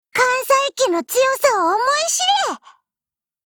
Cv-407023_battlewarcry.mp3 （MP3音频文件，总共长3.5秒，码率320 kbps，文件大小：136 KB）
贡献 ） 协议：Copyright，人物： 碧蓝航线:小齐柏林语音 您不可以覆盖此文件。